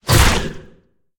File:Sfx creature squidshark flinch 03.ogg - Subnautica Wiki
Sfx_creature_squidshark_flinch_03.ogg